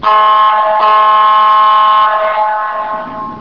Small Ship Horns
Small ‘toot-toot’ - Cabin cruiser horn